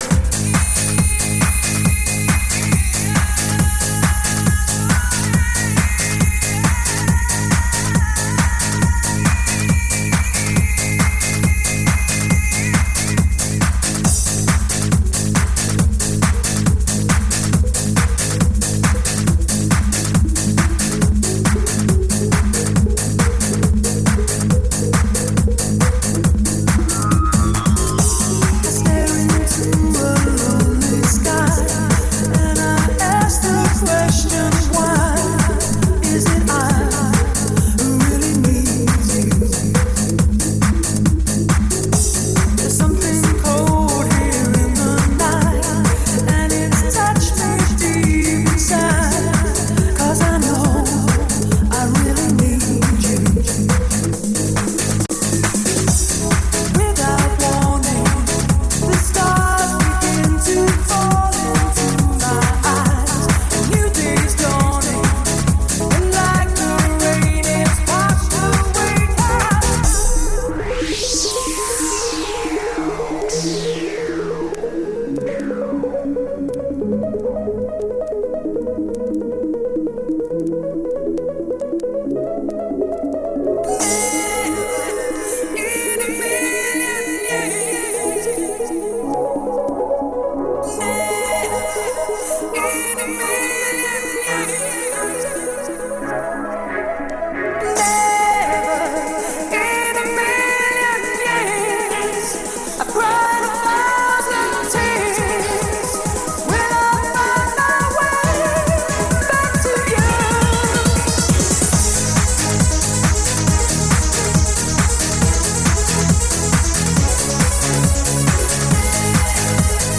A mixalbum 2001 nyarának könnyed hangzásvilágát árasztja